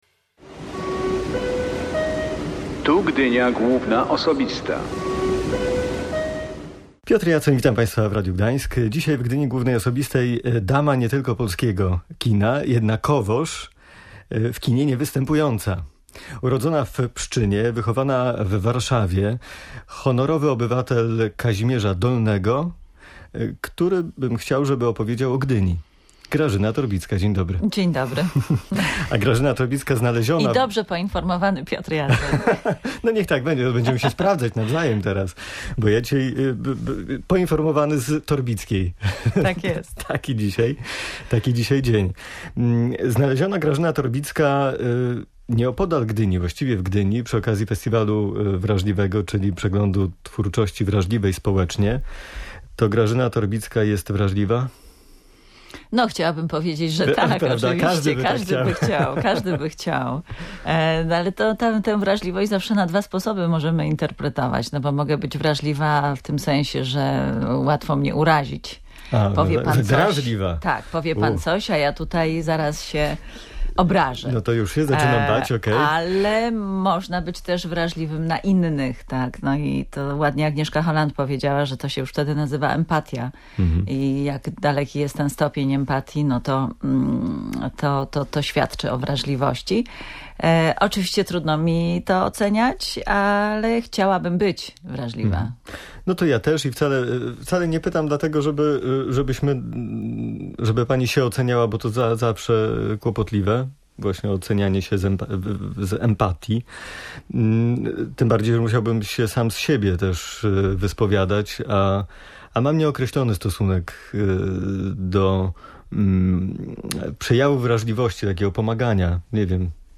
Urodzona w Pszczynie, wychowana w Warszawie, honorowa obywatelka Kazimierza Dolnego, która dziś opowie o Gdyni – tak rozmowę z dziennikarką, Grażyną Torbicką zapowiedział Piotr Jacoń. Dziennikarka wspominała festiwale filmowe w Gdyni, będące pożegnaniami Marcina Wrony, ale też Andrzeja Wajdy.